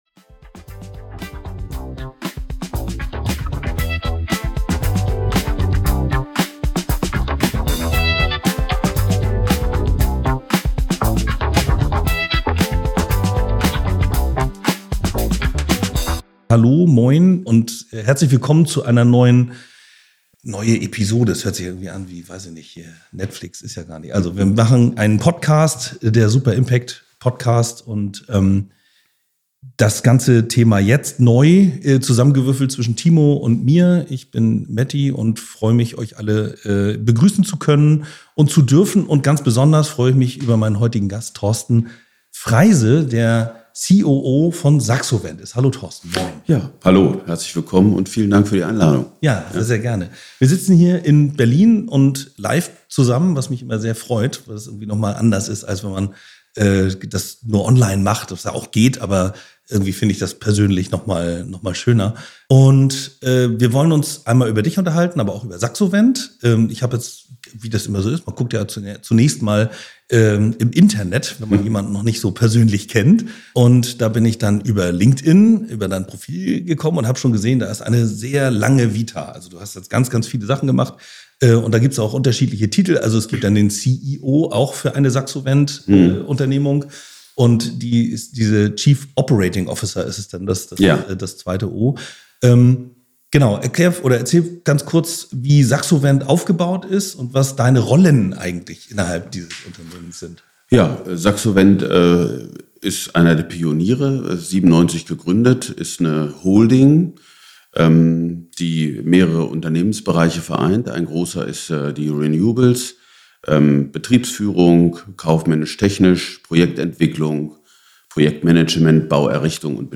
Wie gelingt echte Transformation, wenn Infrastruktur, Kapital und Kommunikation zusammenspielen? Ein Gespräch über Haltung, Realität und Hoffnung in der Energiewende.